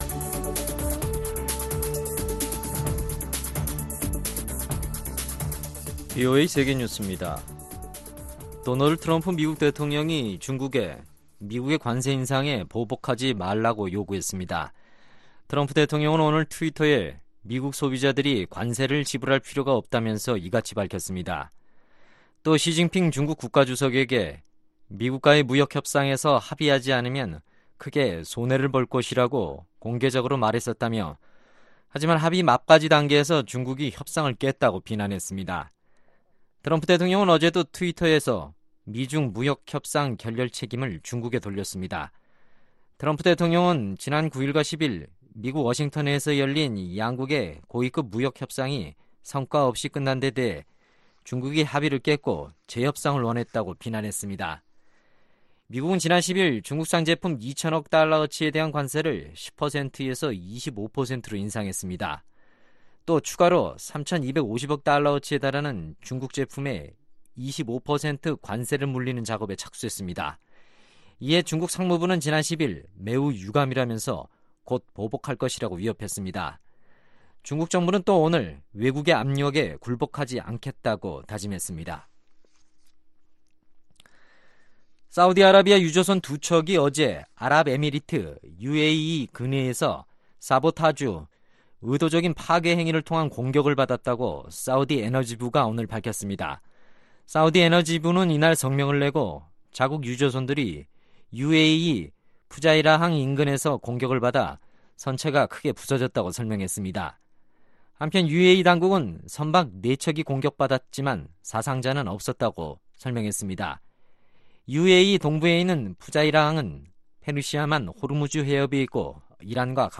VOA 한국어 간판 뉴스 프로그램 '뉴스 투데이', 2019년 5월 13일 2부 방송입니다. 트럼프 미국 대통령은 북한의 단거리 미사일 발사가 신뢰 위반이라는 일각의 해석에 동의하지 않았습니다. 한국인 절반 이상이 국제 공조를 통해 북한에 대한 제재를 확실하게 이행해야 한다고 생각하는 것으로 나타났습니다.